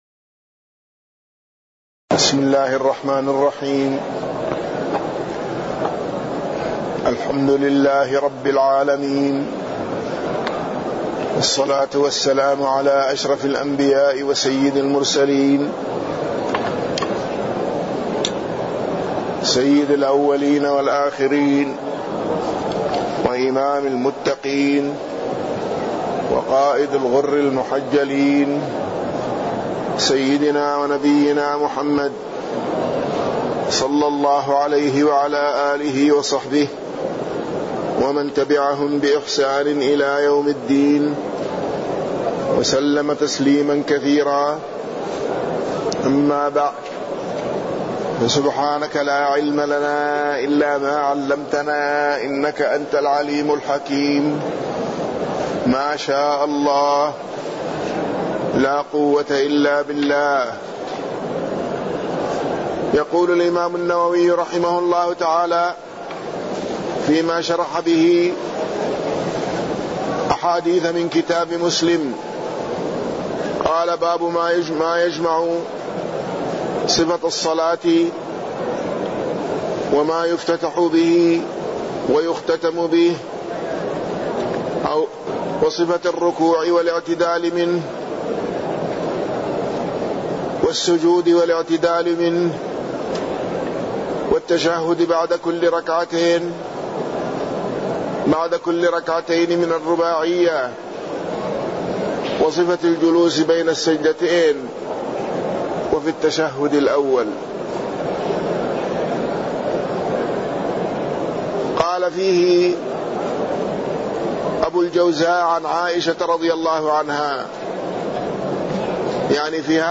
تاريخ النشر ٩ ربيع الأول ١٤٢٩ هـ المكان: المسجد النبوي الشيخ